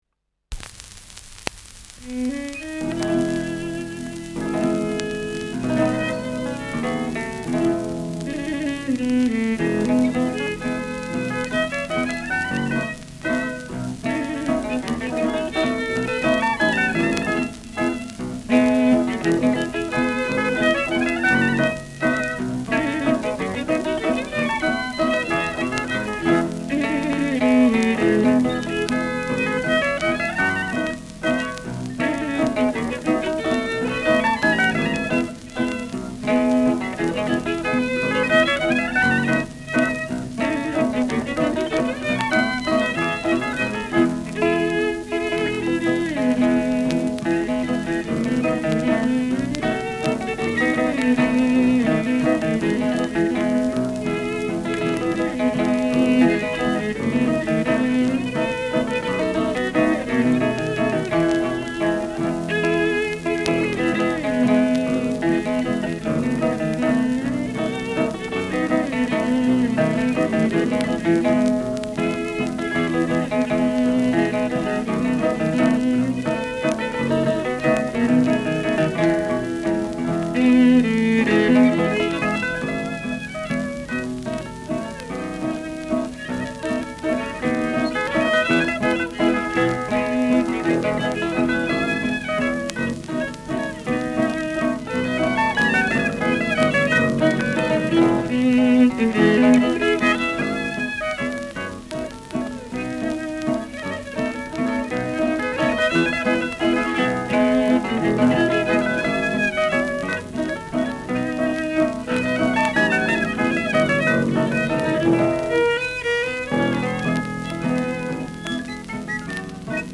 Schellackplatte
Stubenmusik* FVS-00016